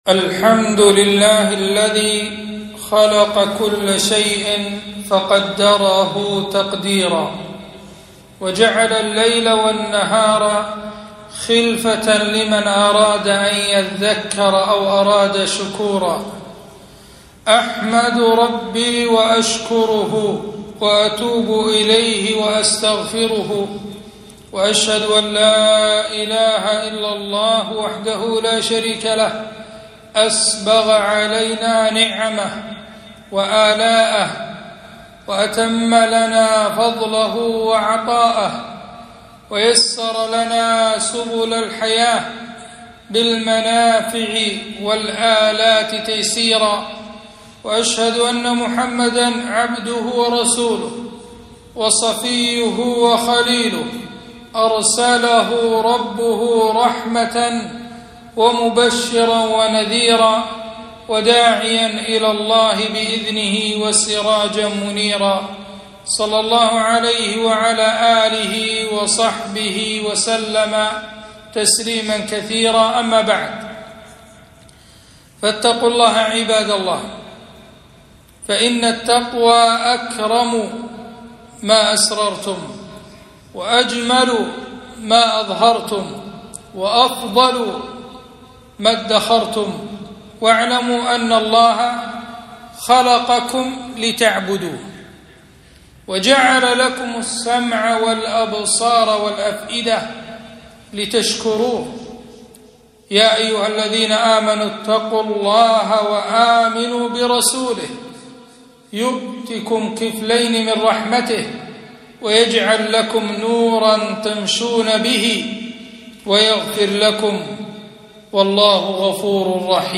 خطبة - آداب السير والمرور